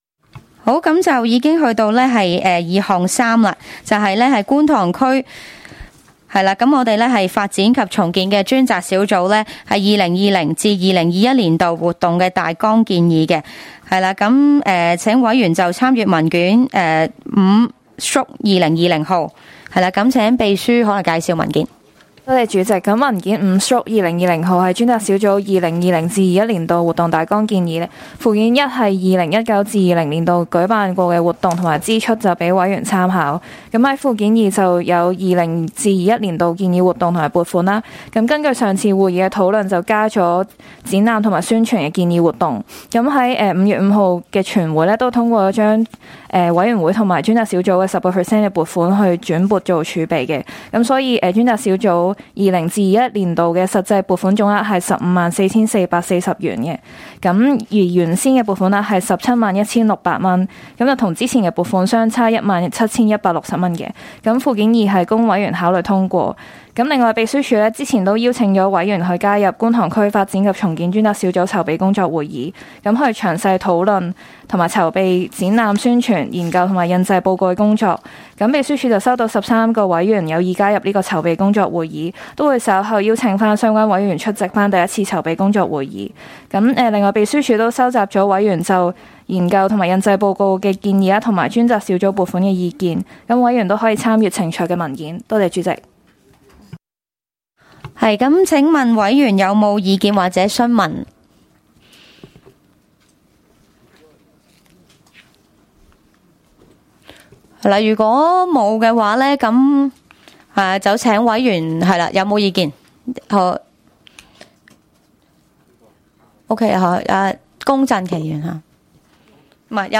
工作小組會議的錄音記錄